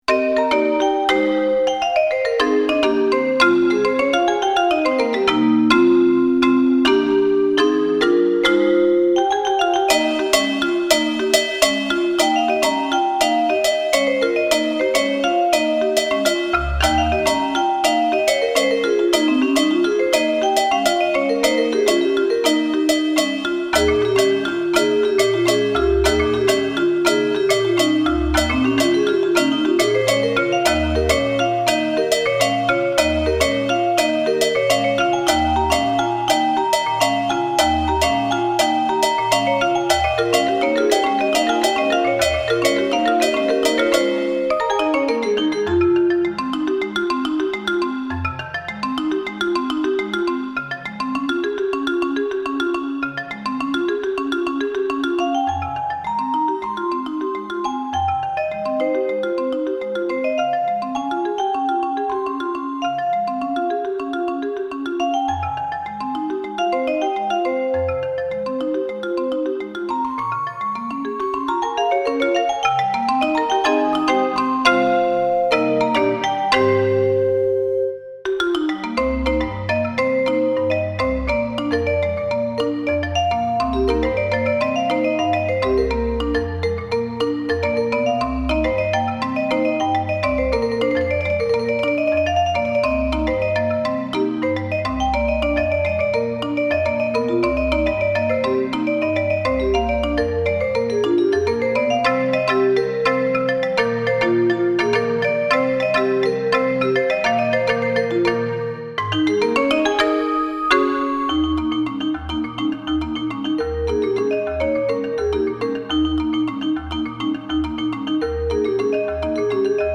This version is for Marimba and Vibraphone.